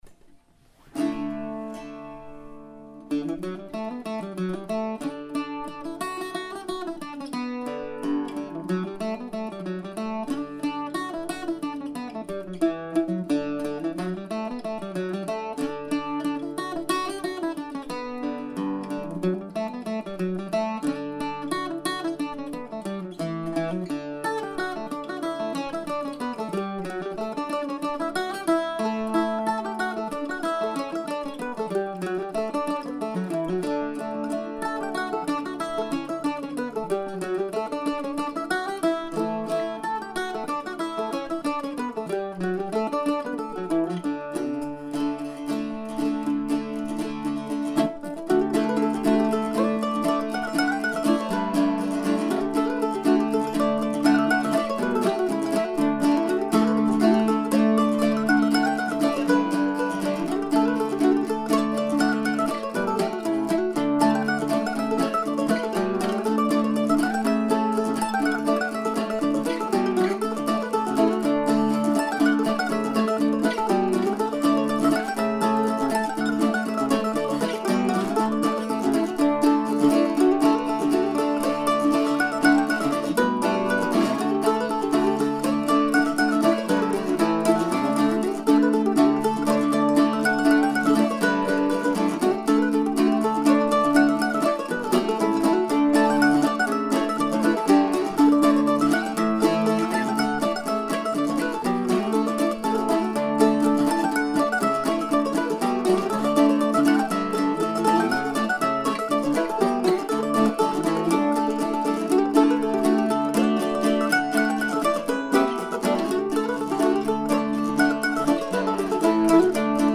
I thought I would tune my old Flatiron octave mandolin in the car before I went into the noisy hall but a little snatch of melody fell out in the process.
The recording here was hastily done last Saturday morning while preparing to play a contra dance at the schoolhouse with Contratopia. I used the Flatiron octave (early 80's, pre-Gibson) and my Pomeroy.